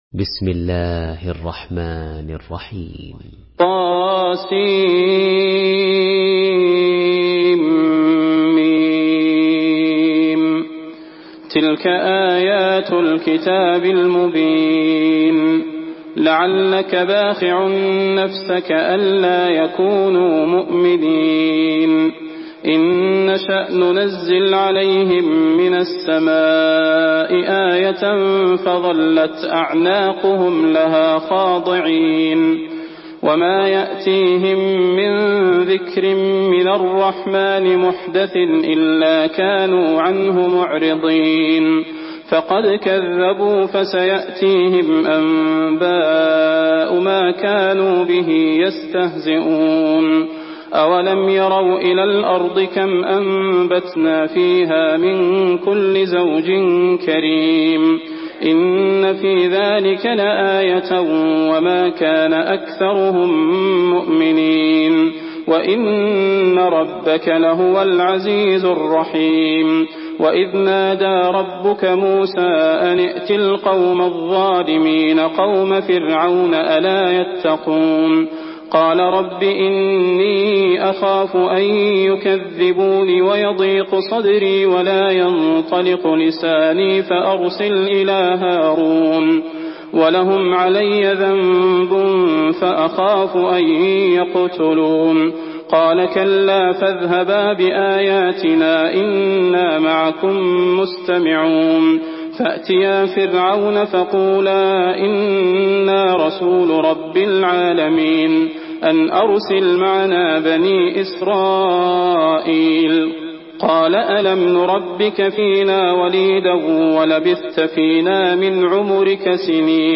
Surah Ash-Shuara MP3 in the Voice of Salah Al Budair in Hafs Narration
Murattal Hafs An Asim